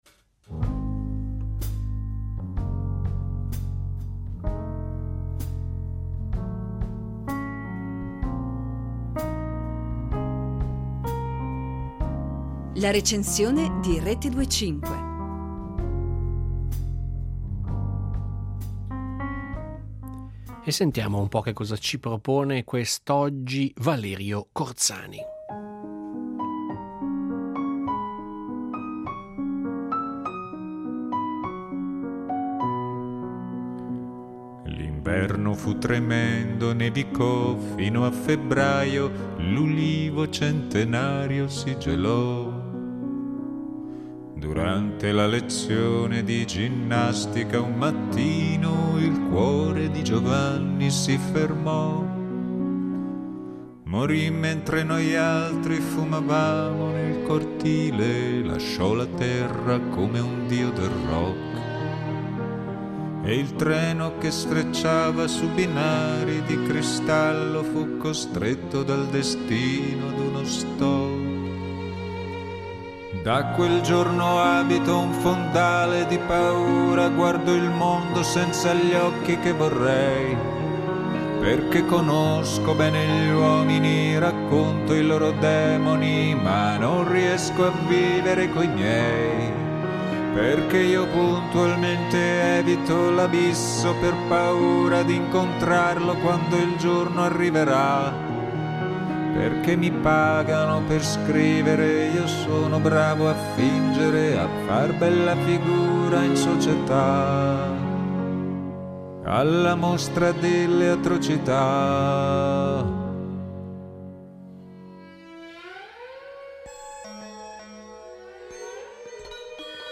La Recensione